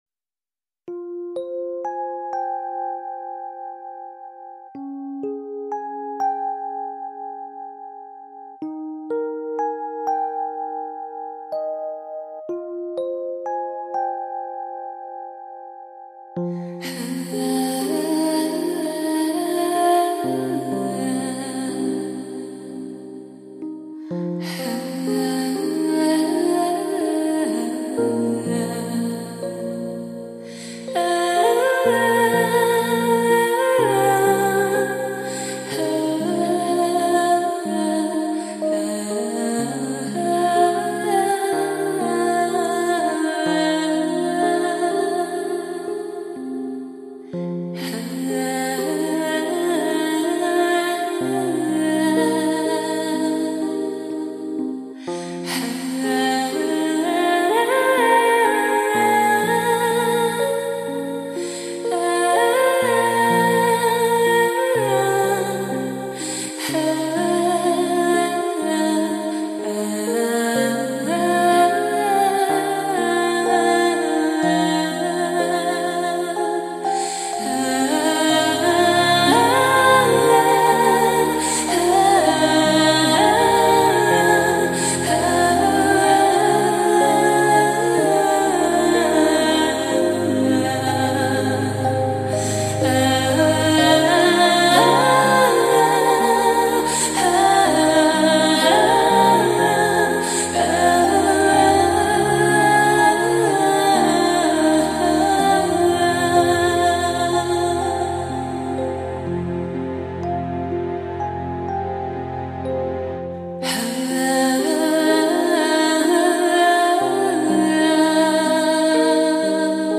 古典民乐, 浅吟心殇, 网络美文 你是第13003个围观者 5条评论 供稿者： 标签：, , ,